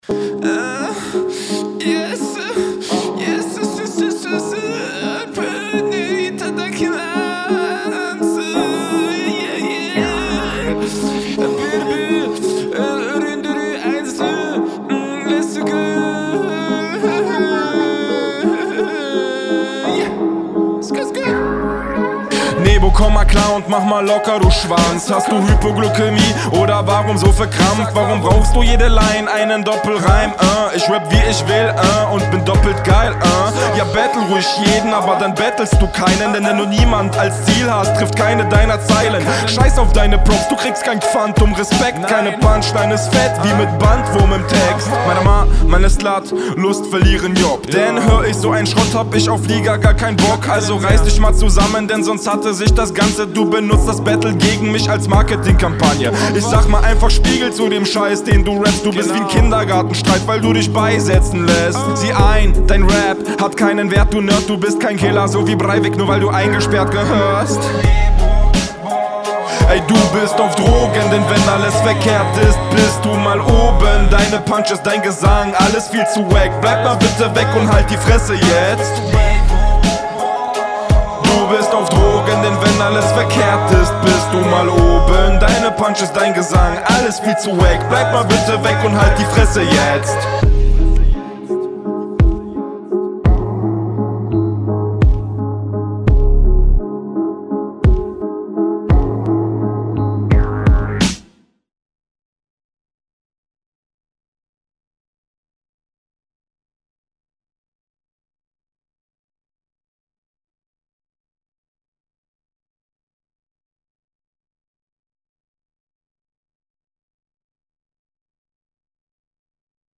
Flow: Bei dir wirkt alles bisschen freestyliger und an paar Stellen stolperst du etwas, aber …